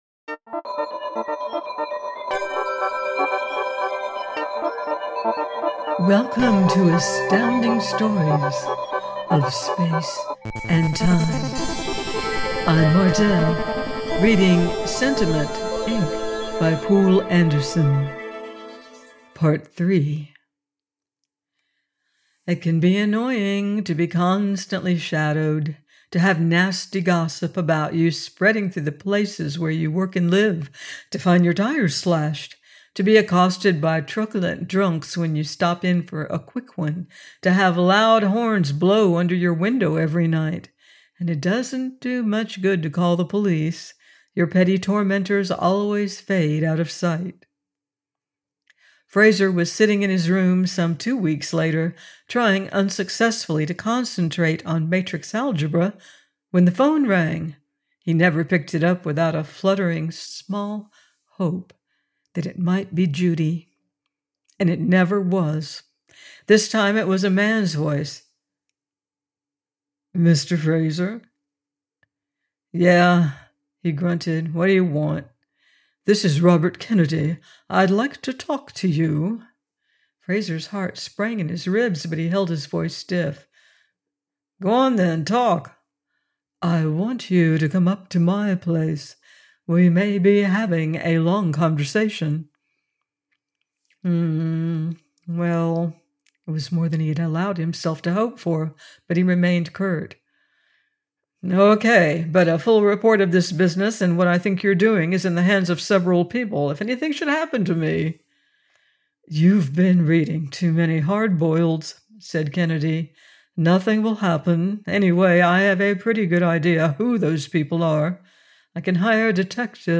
Sentiment Inc. – by Poul Anderson – part 3 - audiobook